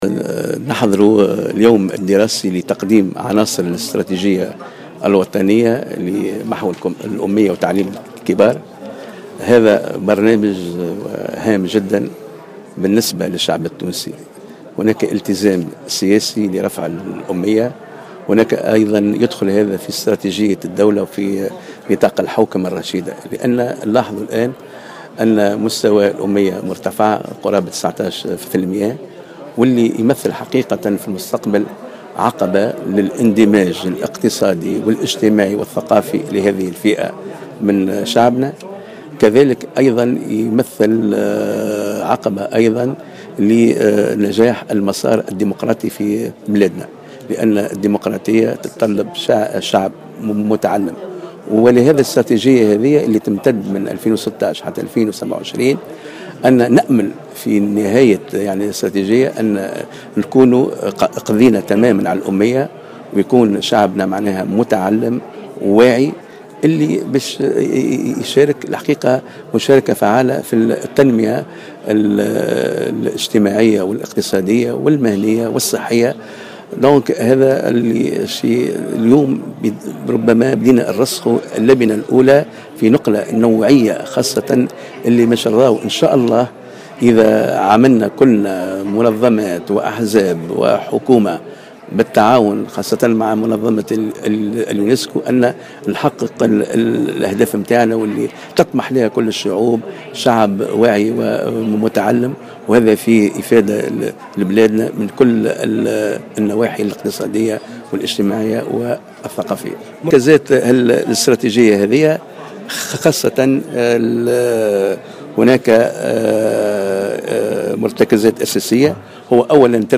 Le chef du cabinet du ministre des affaires sociales, Mohamed Ben Gharbia, a annoncé ce vendredi 30 octobre 2015, lors d’une conférence de presse, l’augmentation du taux d’analphabétisme en Tunisie.